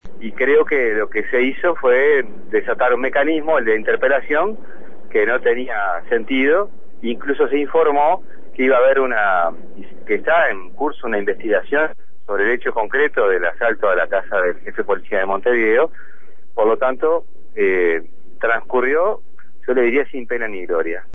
Por su parte, el diputado del Frente Amplio, José Carlos Mahía, dijo que el llamado a sala no tuvo sustento alguno y consideró suficientes las declaraciones del ministro.